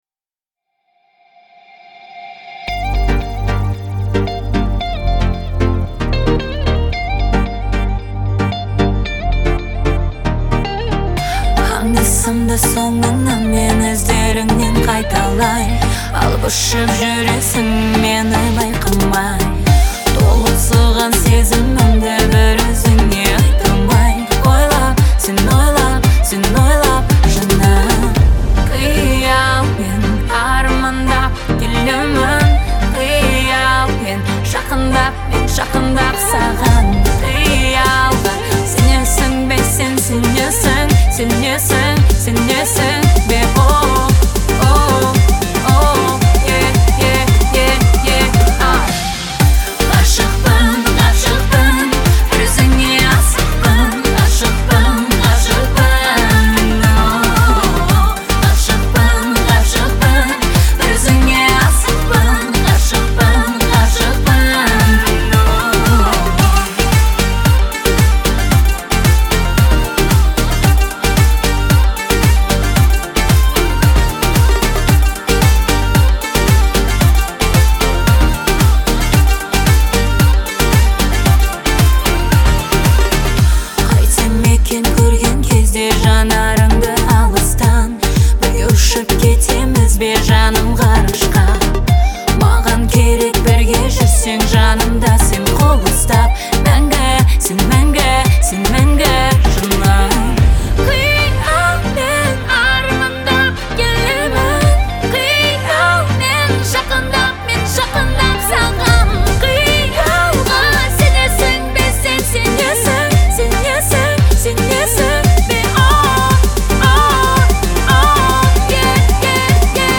мелодичными аранжировками и выразительным вокалом